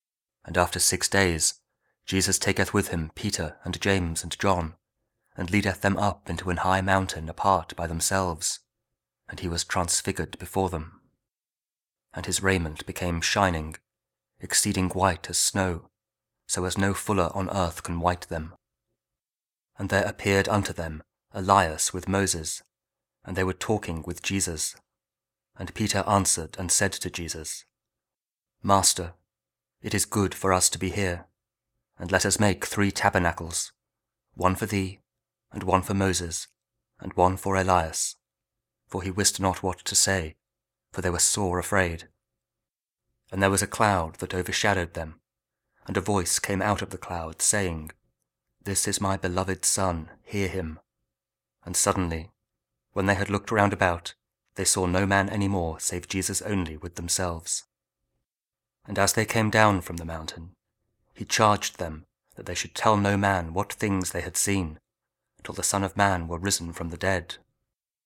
Mark 9: 2-10 – Lent Week 2, Sunday (Year B) ; also, Mark 9: 2-13 for Week 6 Ordinary Time, Saturday (King James Audio Bible, Spoken Word)